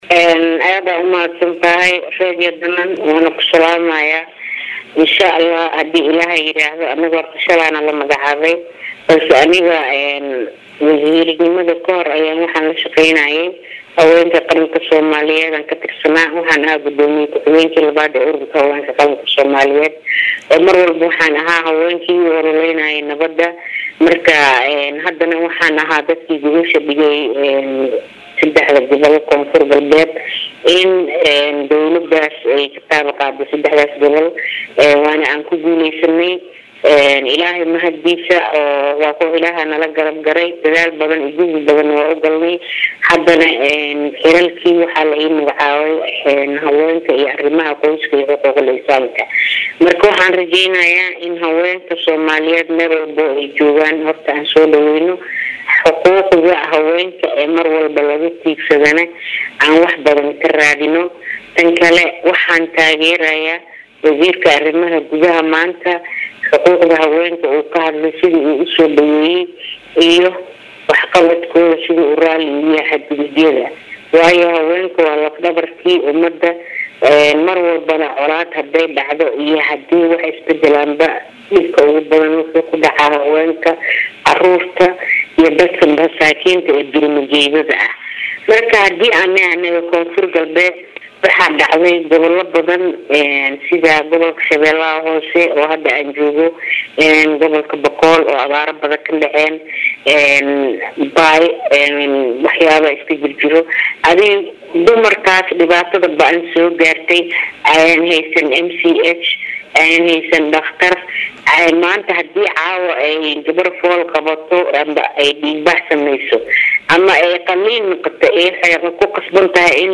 Marka(INO)-Binti ibraahim cali oo ah wasiir ku xigeenka haweenka iyo arimaha qoyska oo wareysi siisay Warbaahinta ayaa waxay sheegtay in wax badan oo lataaban karo ay ka qabanayaan haweenka soomaliyeed ee ku dhibaateysan gobolada baay,bakool iyo shabeelada hoose.